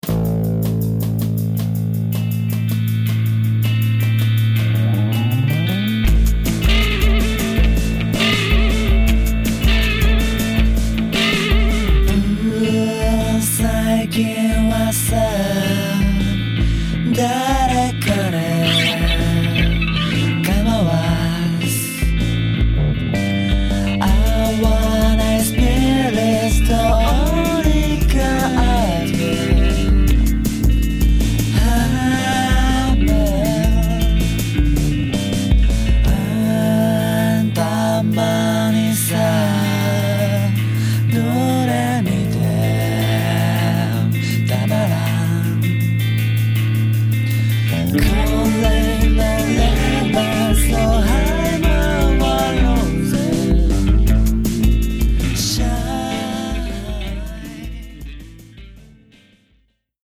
ビートルズと６０年代後期のアートロックがミックスされたような、ちょっとサイケともいえるロック。 ボーカル処理もつぼを心得ている。